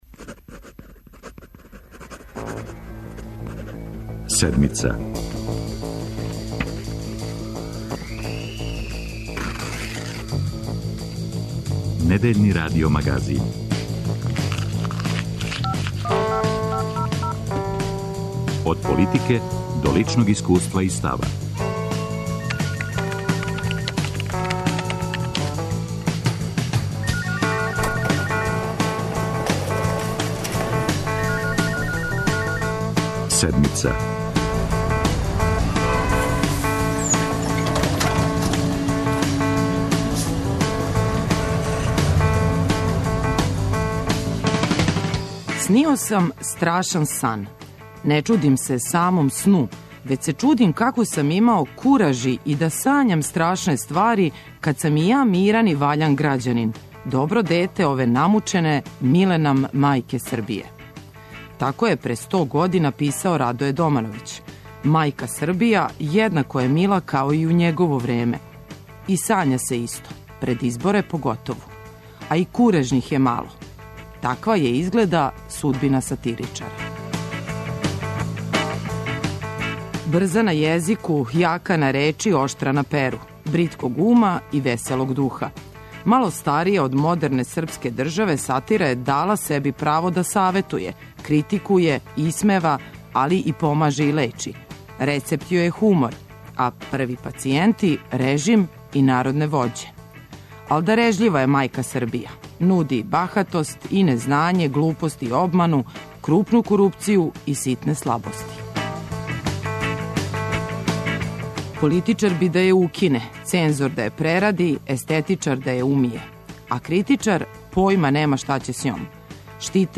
Говоре сатиричари